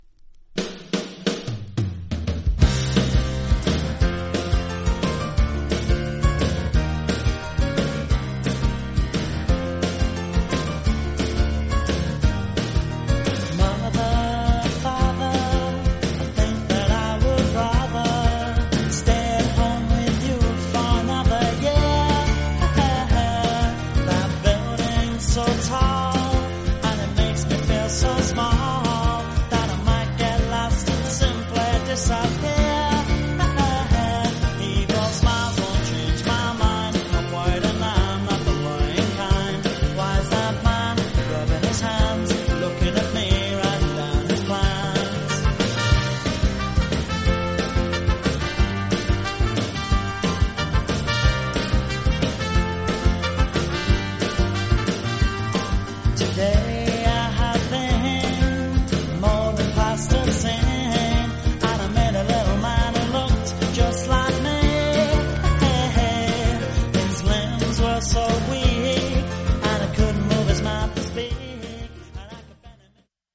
盤面薄いスリキズがそこそこありますが音に影響ありません。
実際のレコードからのサンプル↓ 試聴はこちら： サンプル≪mp3≫